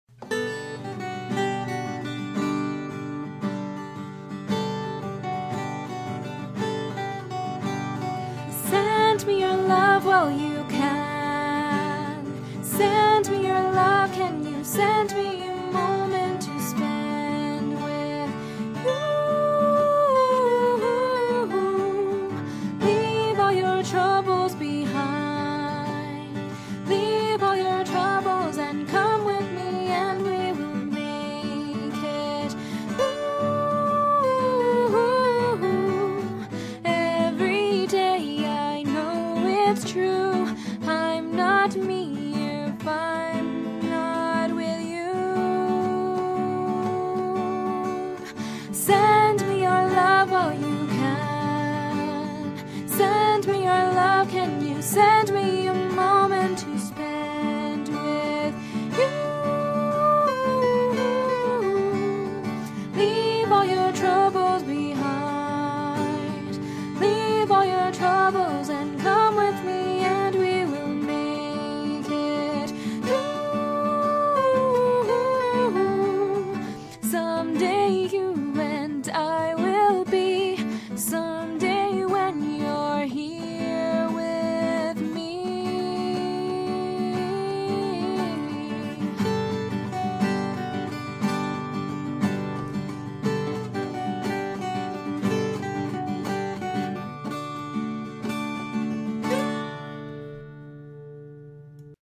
:( Again, usual apology about the crappy quality and music and stuff lol.